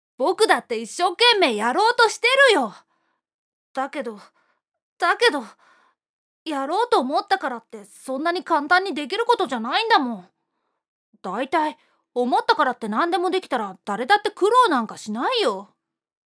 【サンプルセリフ】
（思わず草薙に本音をぶつける）